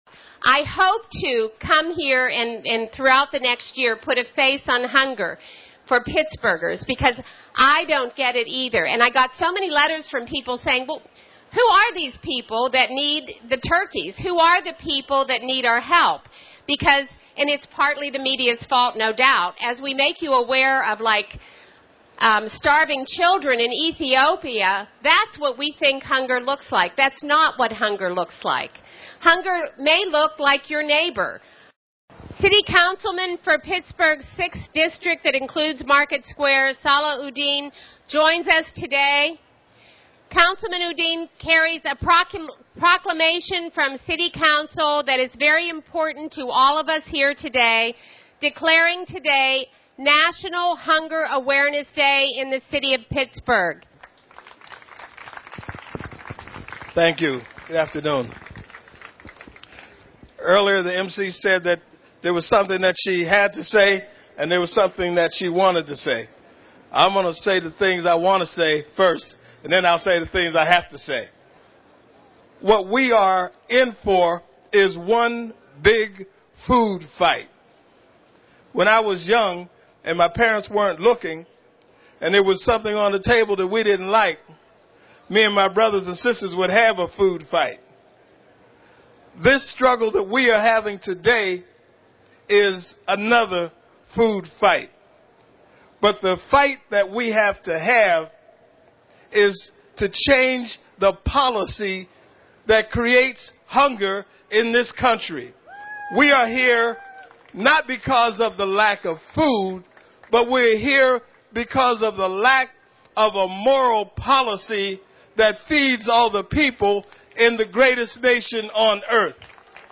Councilman Sala Udin speaks at Market Square.
hunger_awareness_day_excerpts.mp3